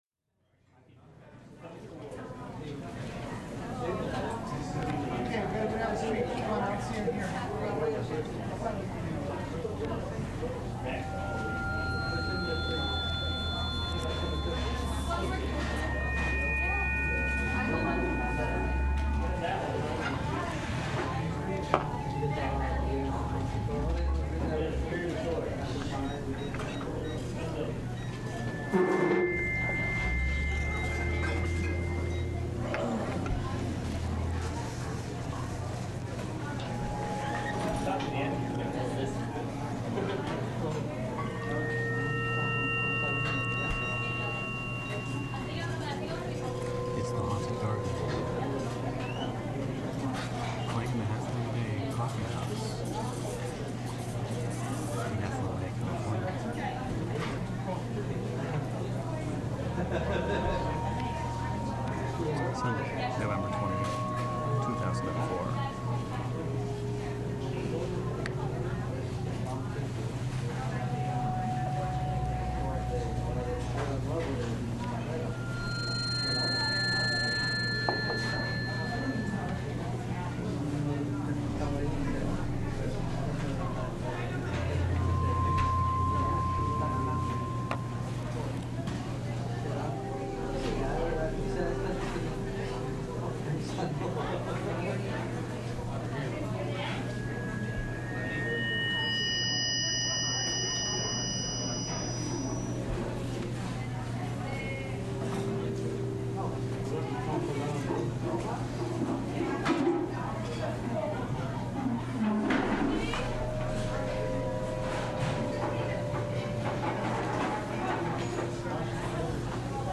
I then recorded the system harmonizing with ambient sounds.
Sitting at a counter by the window.
The ocean half a mile away, inaudible. Lulling roar of cafe patrons with harmonic commentary by Haunted Garden.